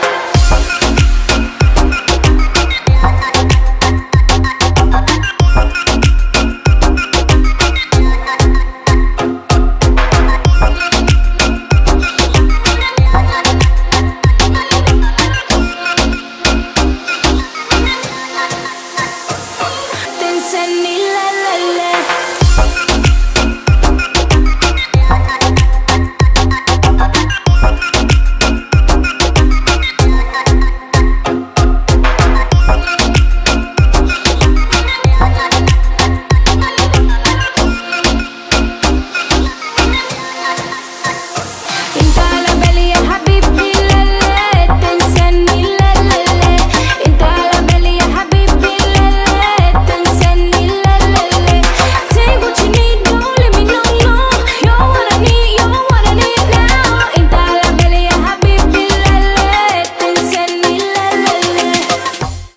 • Качество: 320, Stereo
поп
женский вокал
спокойные
electro